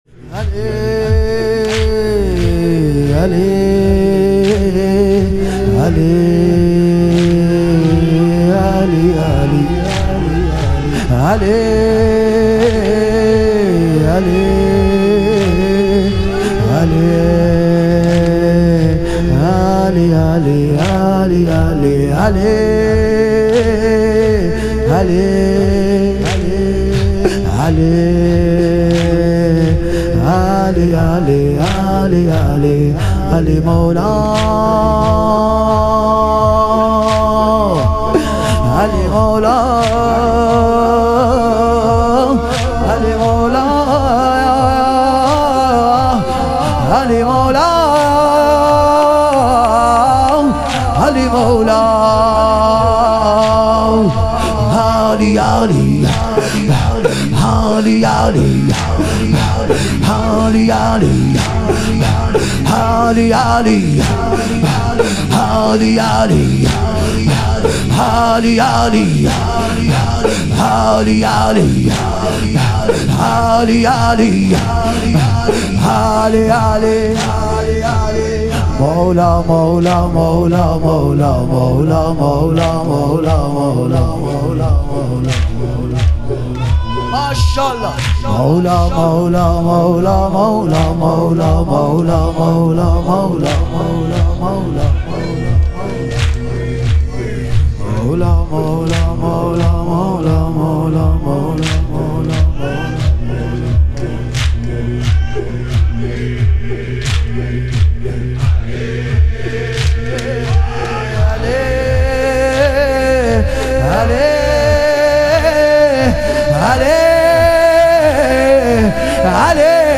ایام فاطمیه دوم - واحد